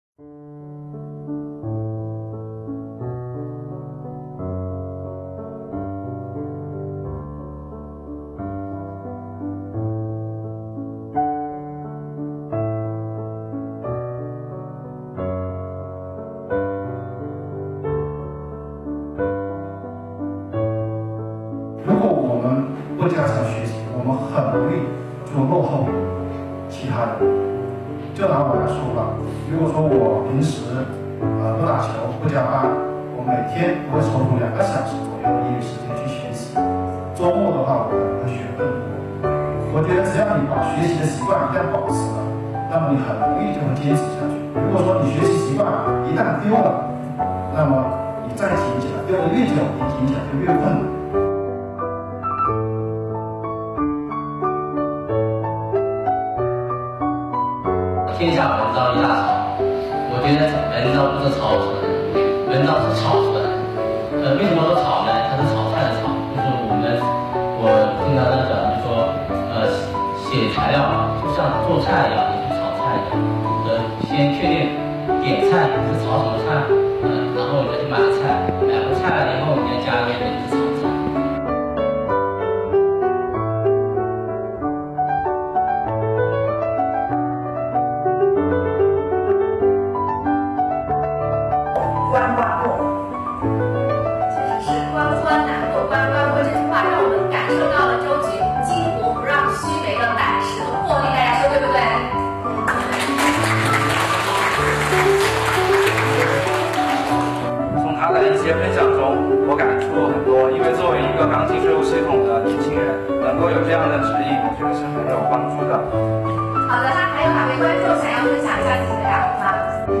知识竞赛：党史学习正当潮
通过举办“学党史 感党恩 听党话 跟党走”——庆祝中国共产党成立100周年党史知识竞赛，回顾党的光辉历程，加深税务干部队伍对党史的理解和认识，进一步激励和引导税务干部把理论学习转化为锐意进取、开拓创新的实际行动，切实做到学党史、感党恩、听党话、跟党走。